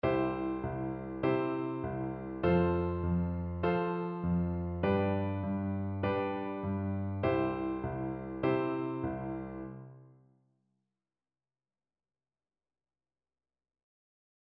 Zurück zur rechten Hand: Wie wäre es mit Halben Noten anstelle der schnöden Ganzen? Die linke Hand bleibt zunächst so wie sie ist.
Pattern M: Bleiben wir bei der Bassbegleitung aus Pattern L und ändern wieder die rechte Hand
liedbegleitung-pattern-m.mp3